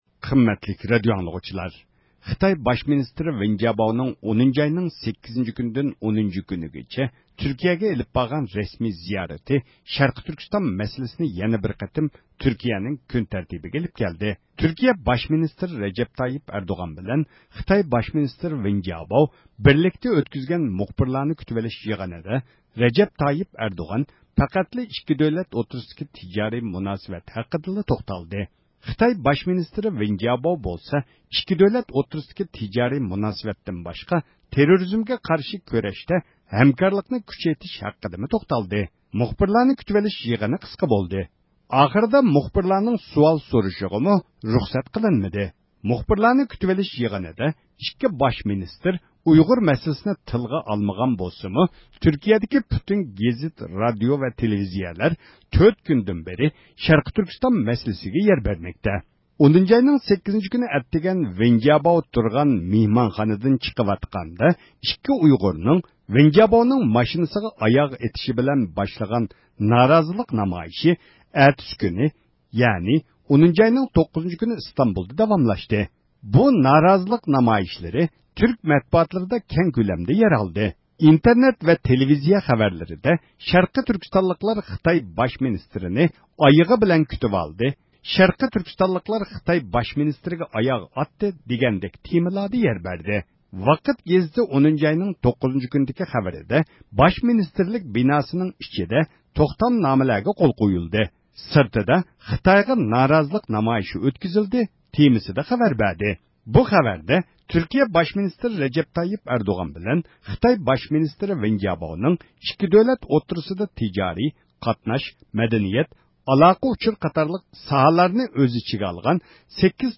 ﻣﯘﺧﺒﯩﺮﯨﻤﯩﺰ